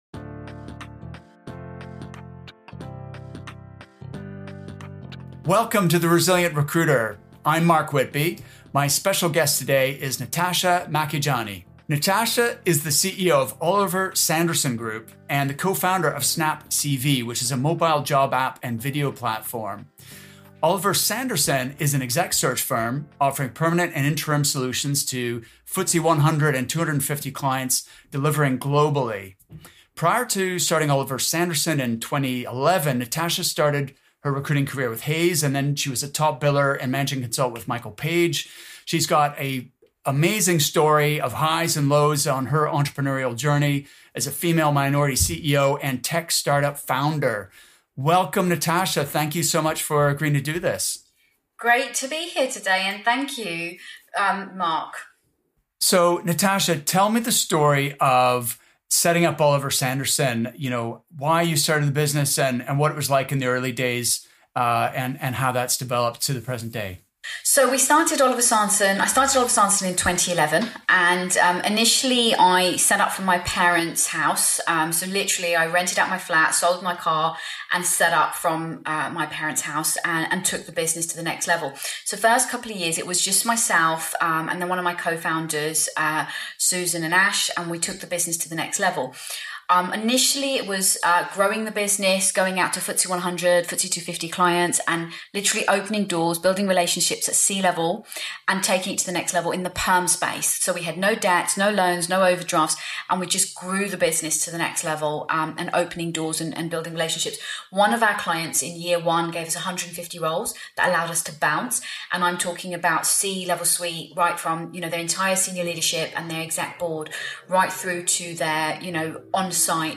In this interview, she shares her strategies and best practices for new business development.